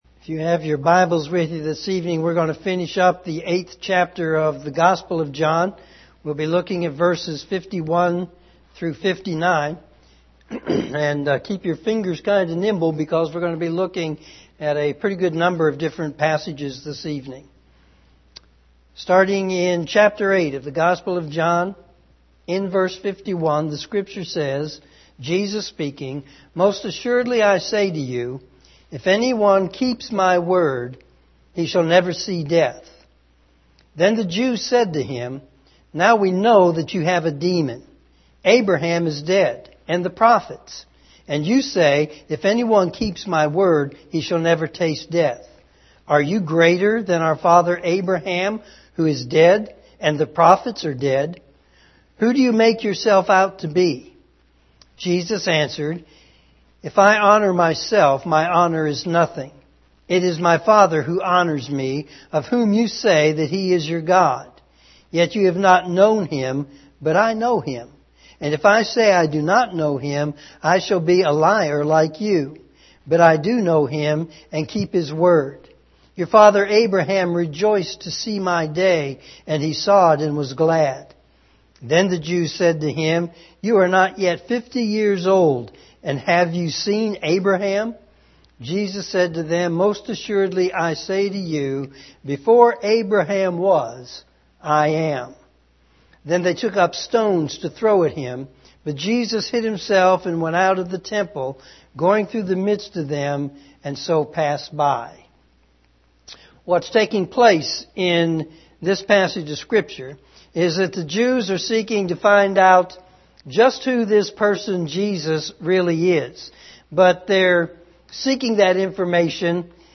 sermon4-8-18pm.mp3